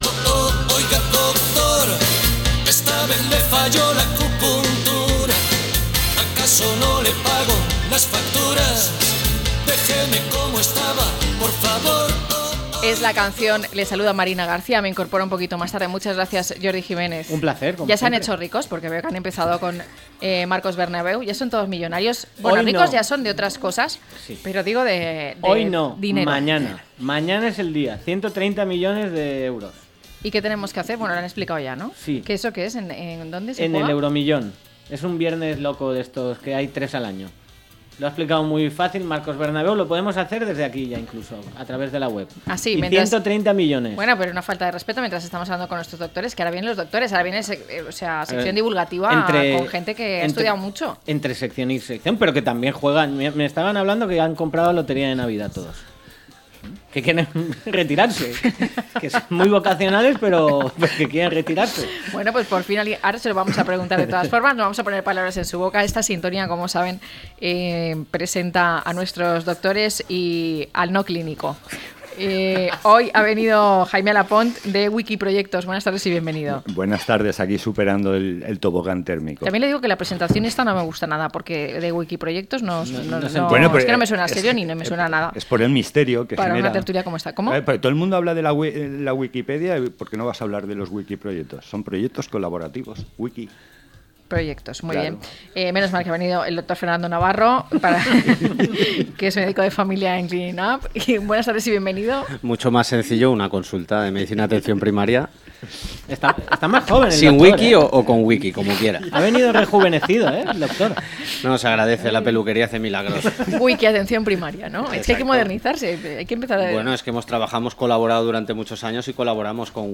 tertulia médica